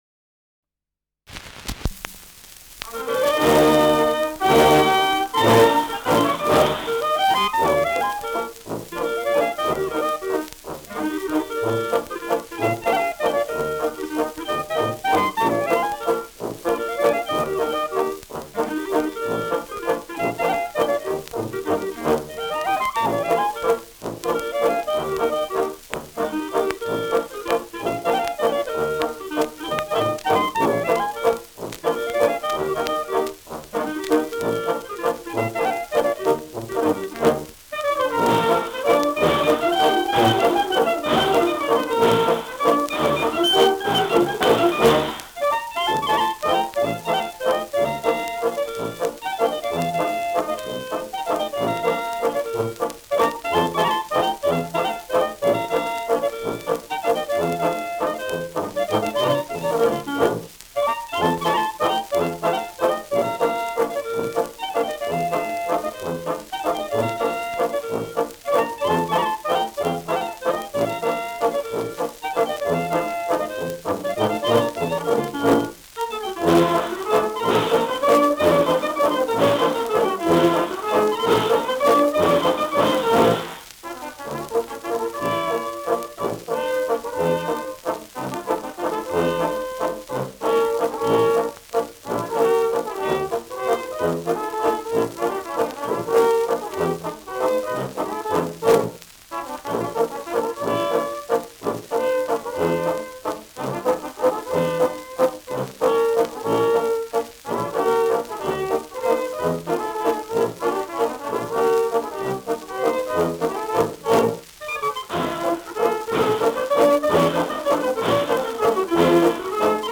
Schellackplatte
Stärkeres Grundrauschen : Verzerrt an lauten Stellen : Gelegentlich leichtes Knacken
Kapelle Durlhofer (Interpretation)